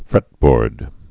(frĕtbôrd)